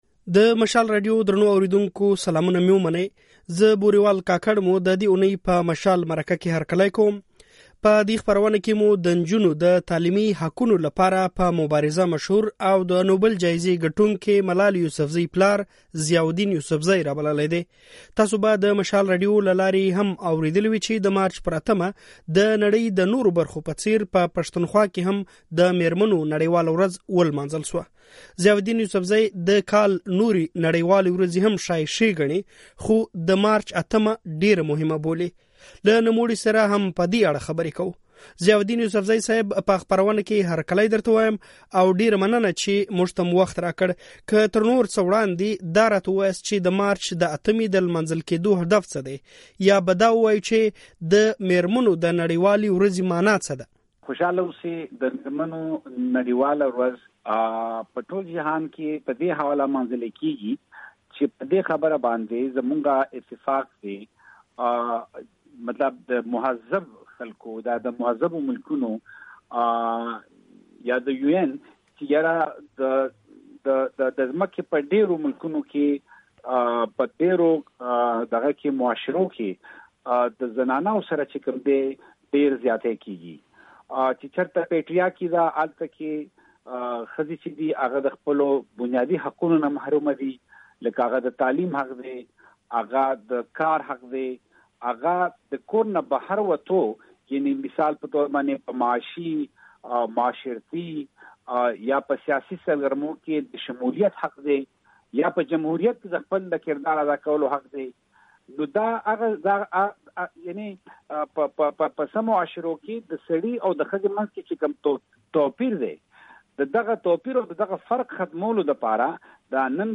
له ضیاالدین یوسفزي سره د مشال مرکه دلته واورئ